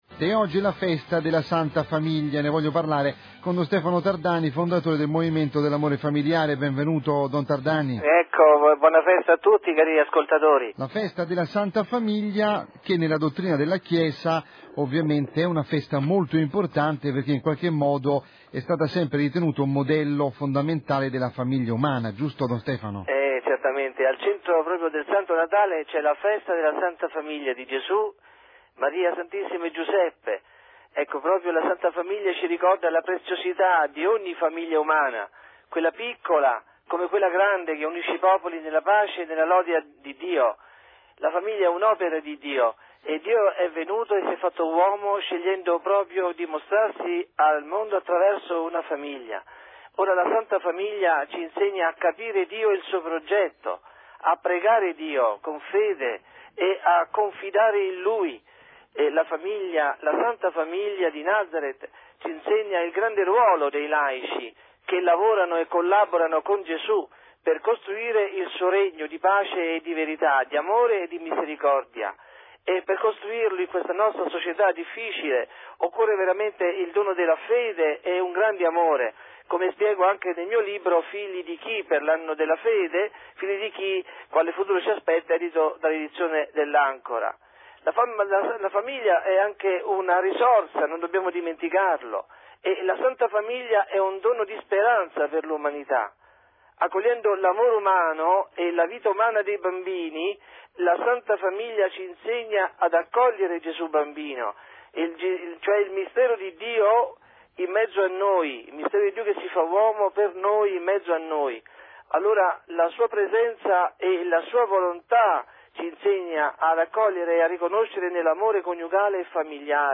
31 dicembre 2012 – Piazza S.Pietro